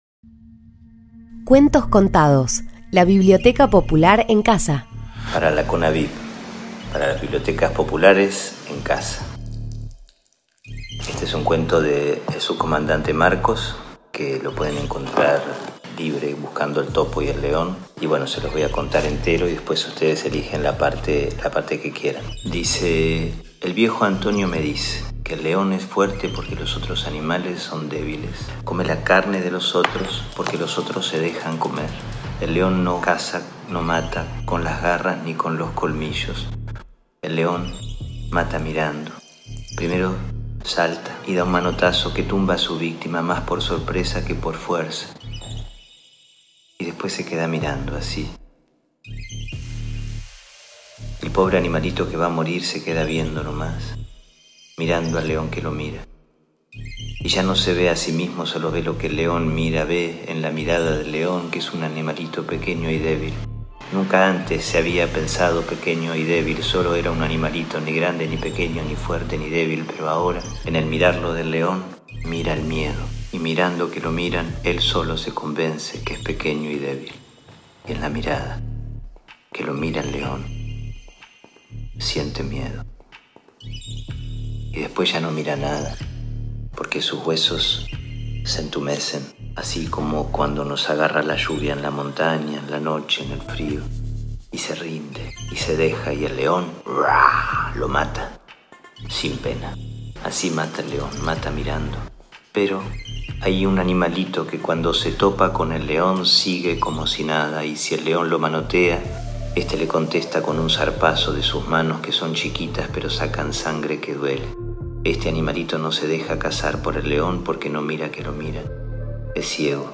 Cuentos contados: El topo y el león. Lee Leonardo Sbaraglia